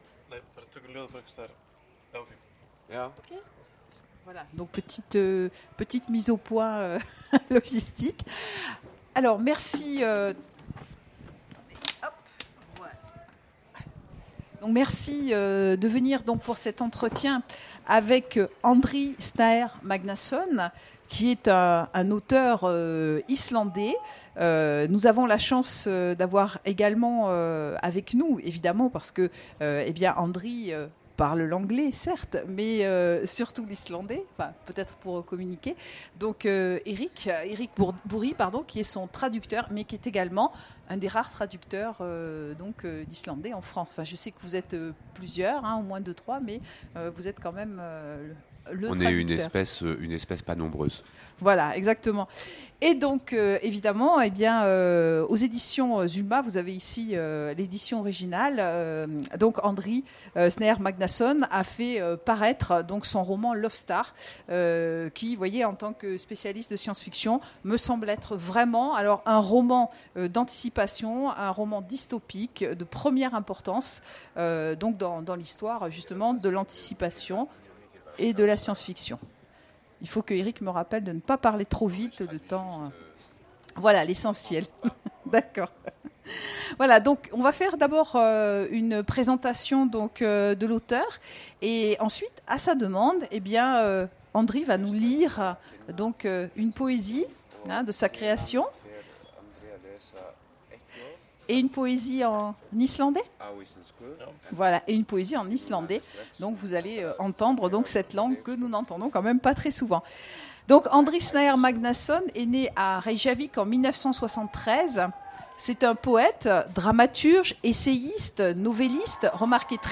Imaginales 2017 : Entretien avec Andri Snaer Magnason
- le 31/10/2017 Partager Commenter Imaginales 2017 : Entretien avec Andri Snaer Magnason Télécharger le MP3 à lire aussi Andri Snaer Magnason Genres / Mots-clés Rencontre avec un auteur Conférence Partager cet article